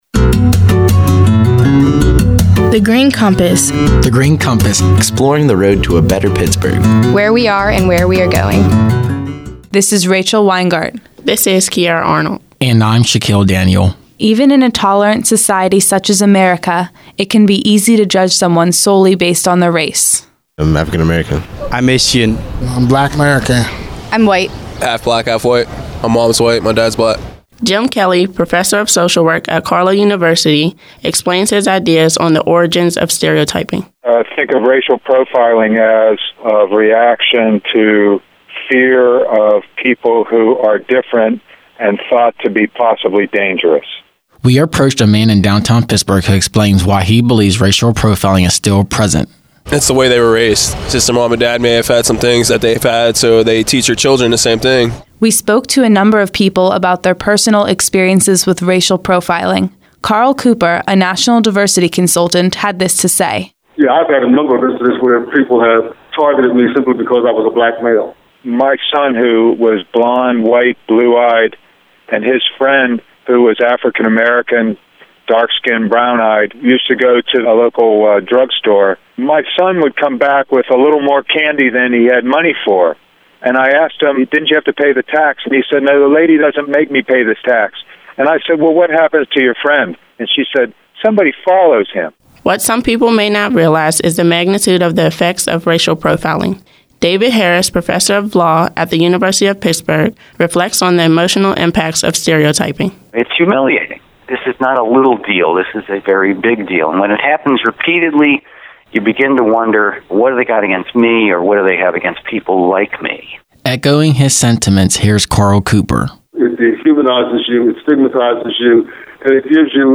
In July 2012, twenty-three students about to enter their first year of college created these nine radio features as Summer Youth Philanthropy Interns at The Heinz Endowments.
interview